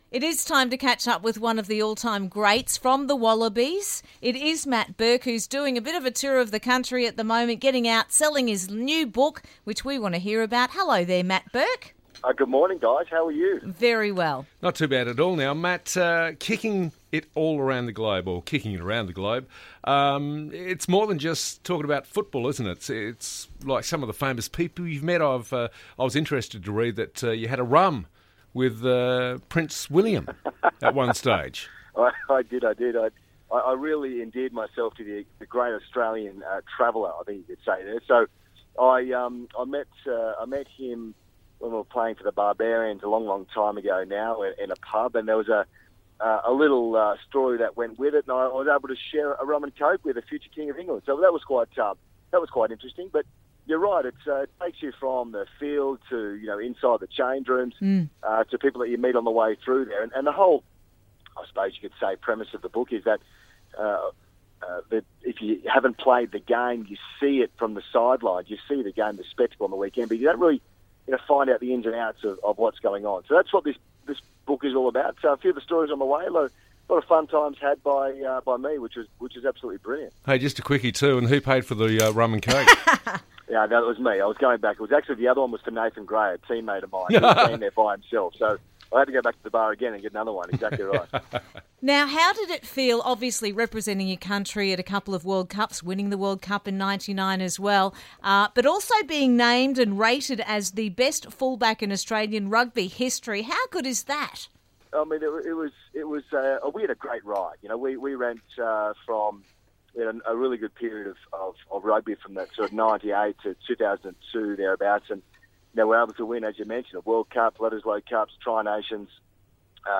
Interview - Former Wallaby Great Matt Burke